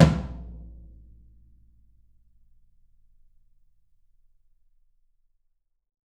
YAM18 ROOM-R.wav